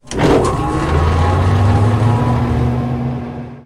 Room ambience
FanOn.ogg